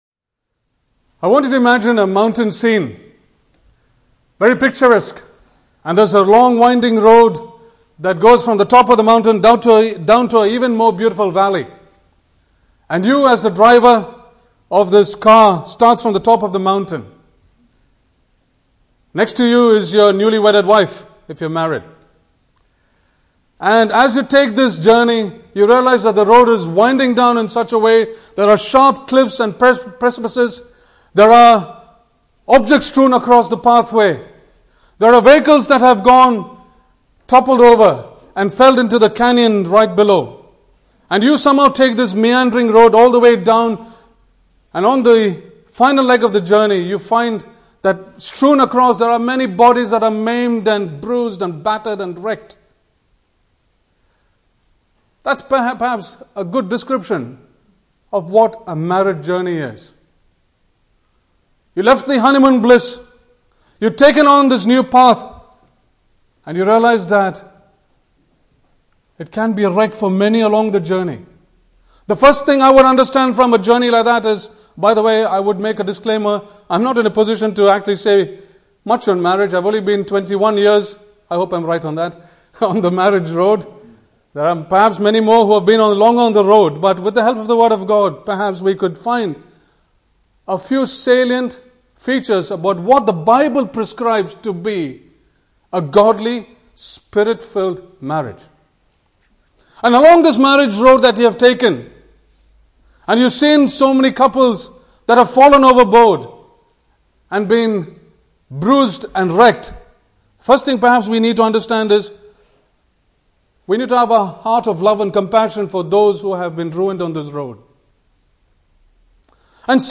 requirements and rhythm of marriage in a wide ranging message aimed at preparing young couples for marriage (Message given Apr 5th 2018)